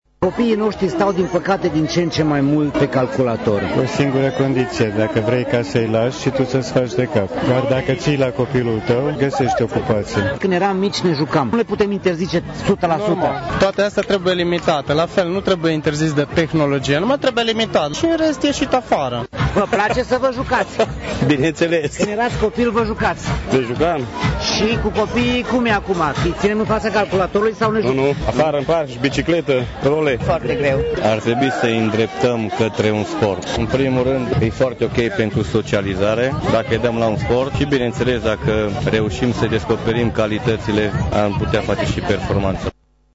Părinții și-au amintit că în copilăria lor se jucau mult mai mult decât o fac acum copiii lor, și au recunoscut că dacă atunci aveau calculatoare sau telefoane inteligente, erau la fel de vulnerabili precum tinerele generații: